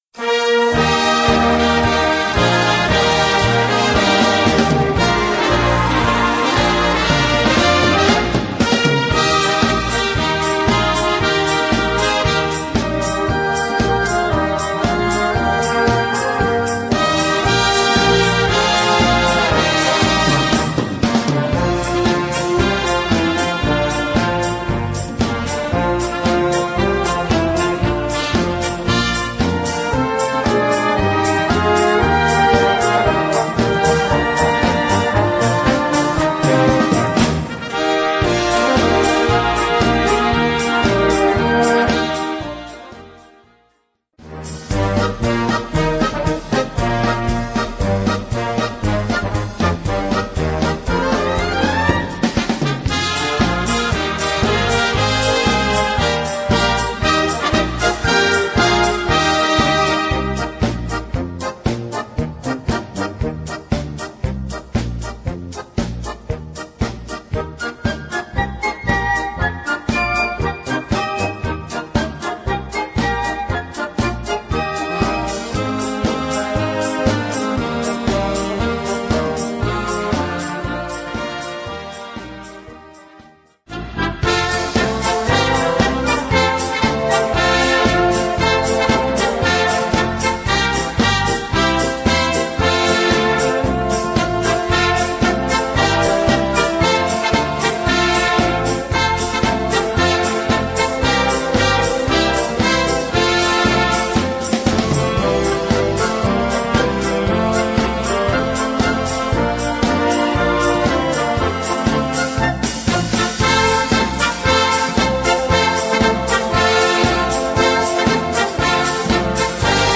Gattung: Volkslieder-Medley
Besetzung: Blasorchester